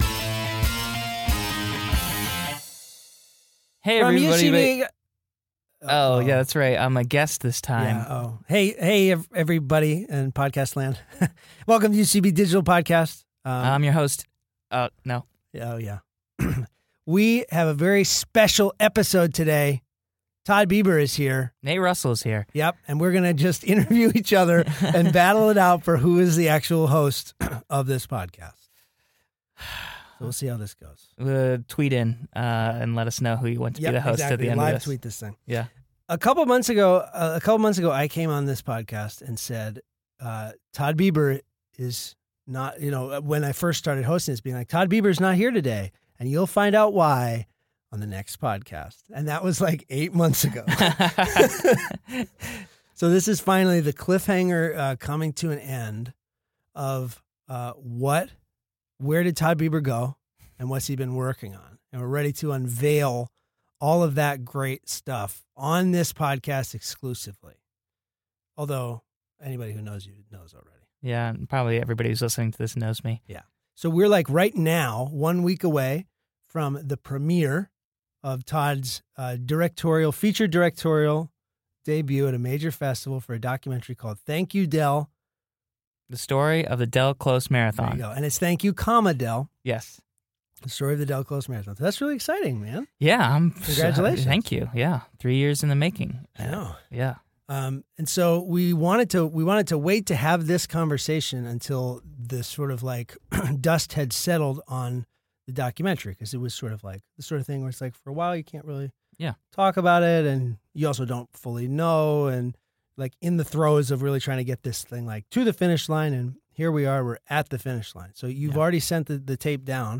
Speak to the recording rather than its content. Recorded at UCB Comedy Studios East in New York City.